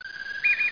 bird2.mp3